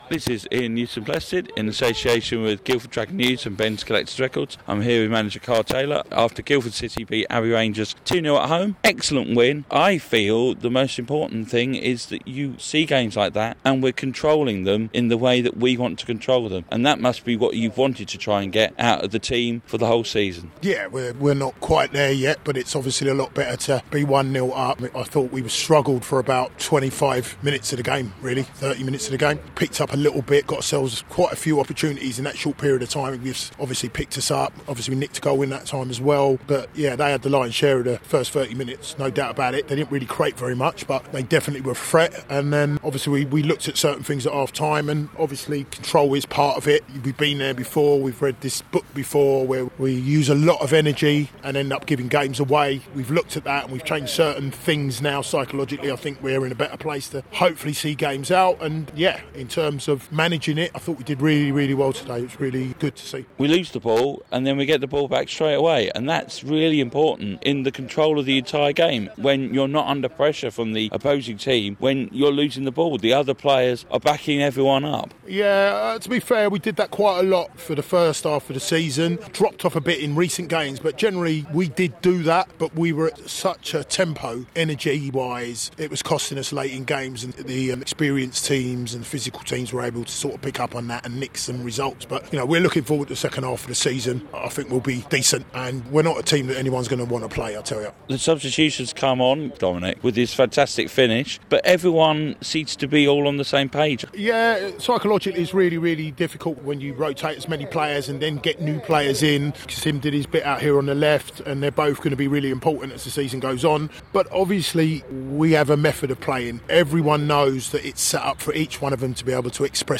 The post-match interview